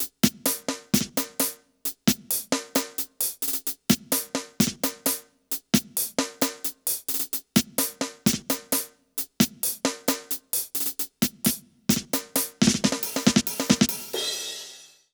British REGGAE Loop 133BPM (NO KICK).wav